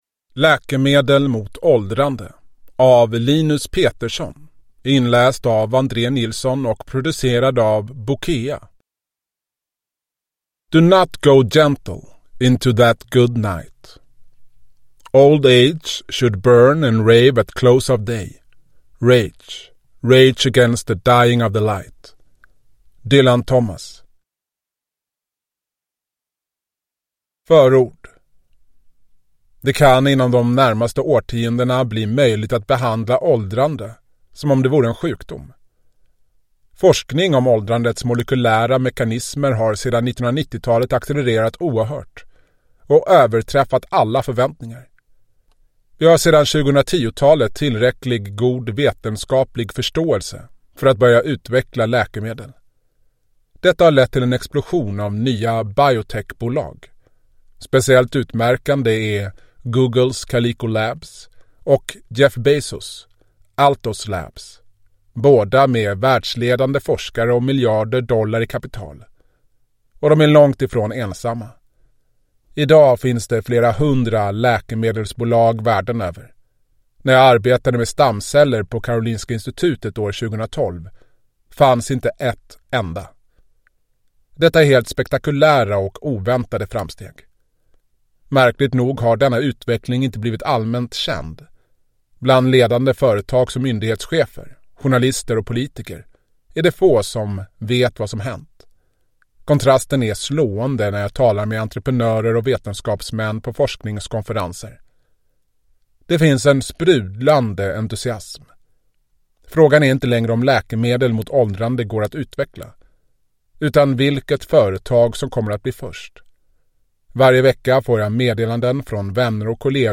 Läkemedel mot åldrande : hur modern vetenskap ger oss obegränsad frisk livslängd – Ljudbok